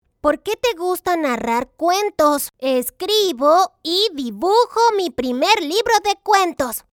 Muestra Voz infantil Masculina
Latin neutral Spanish
VOZ INFANTIL MASCULINA.mp3